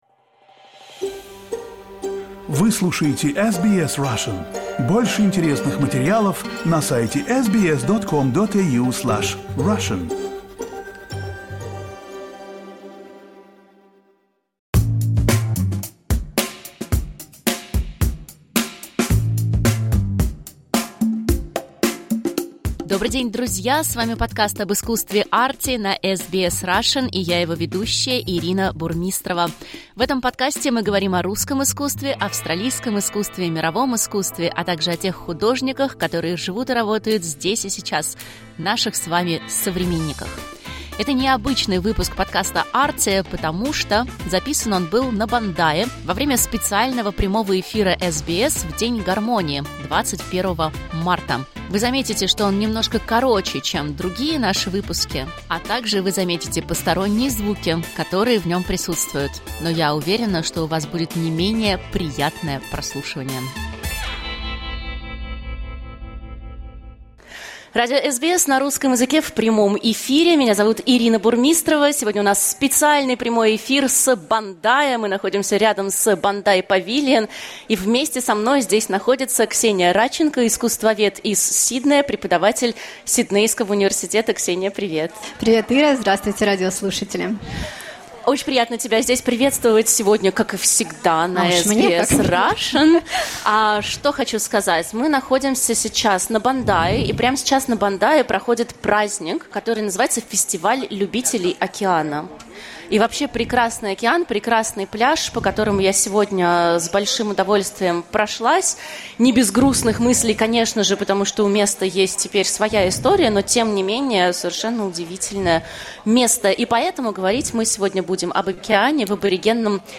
Это не обычный выпуск подкаста Arty, потому что записан он был на Бондае во время специального прямого эфира SBS в День гармонии, 21 марта. Вы заметите, что он немножко короче, чем другие наши выпуски, а также вы заметите посторонние звуки, которые в нём присутствуют.